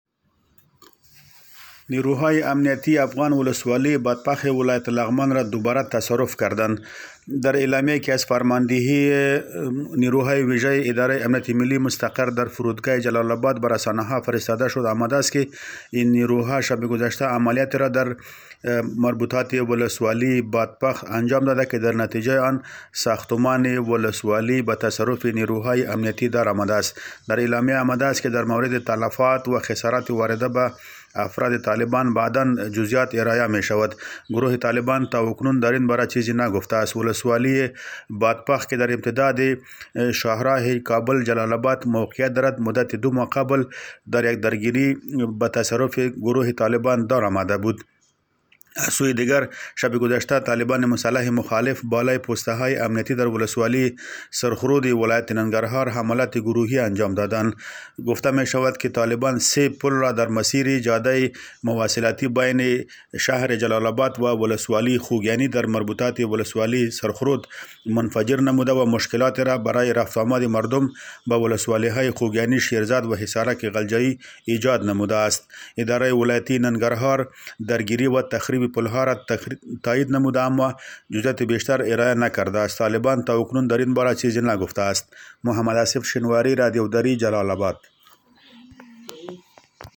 گزارش تکمیلی